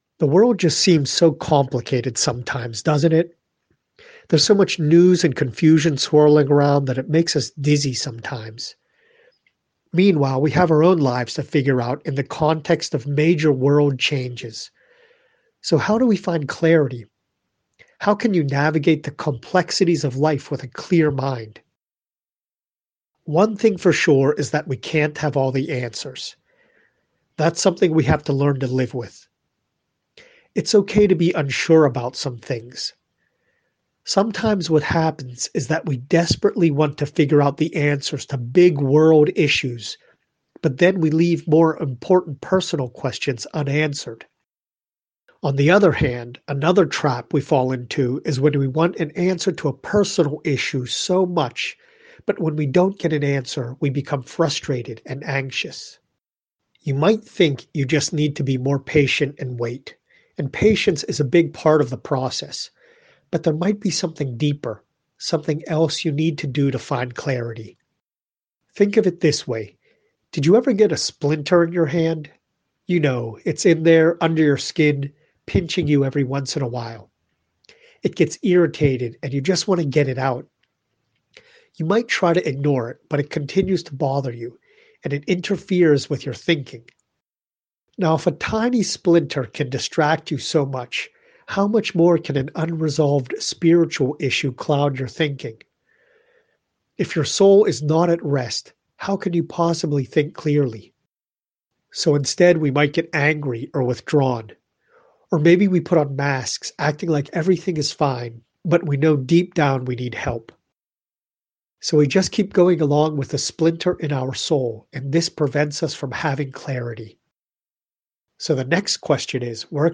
prayer-for-clarity-of-the-mind.mp3